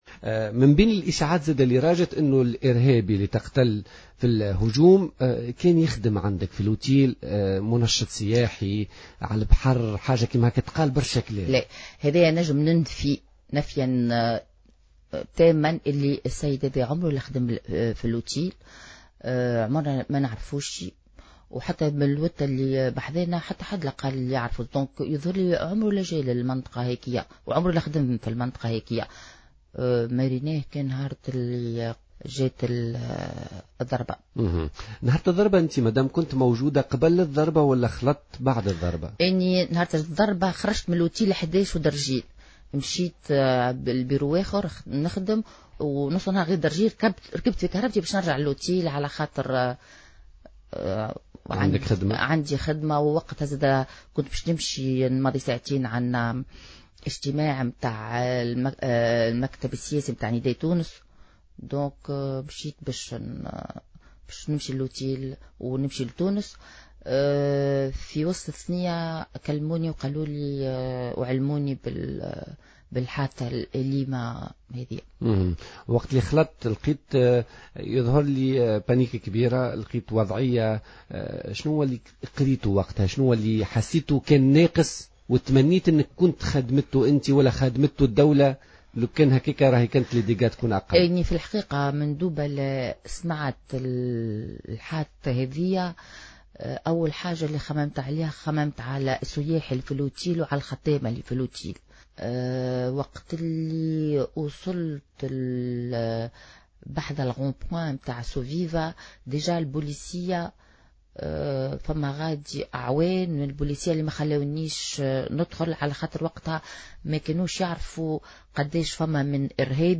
أكدت زهرة إدريس، صاحبة نزل "امبريال مرحبا" ضيفة برنامج "بوليتيكا" اليوم الثلاثاء أن منفذ هجوم سوسة، سيف الدين الرزقي لم يعمل بالنزل المذكور خلافا لما تم ترويجه.